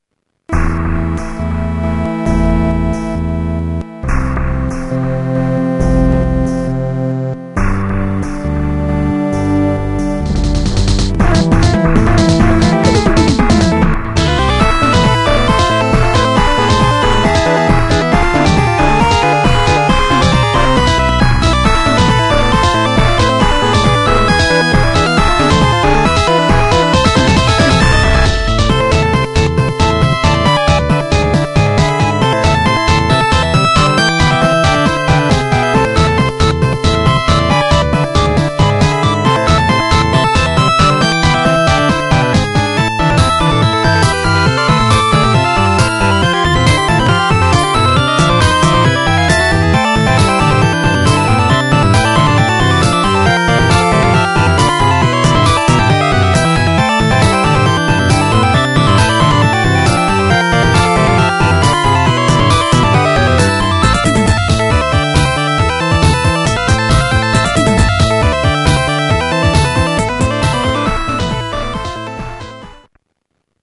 視聴ゲームの終盤　な感じ。